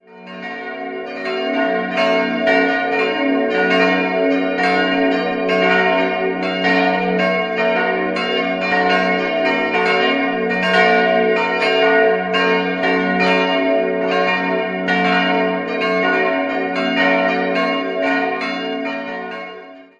4-stimmiges Salve-Regina-Geläute: ges'-b'-des''-es'' Die Glocken wurden 1948/49 von Karl Hamm in Regensburg gegossen.